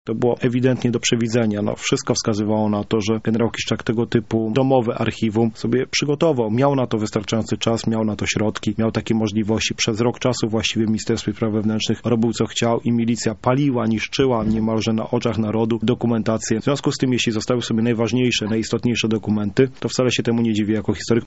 historyk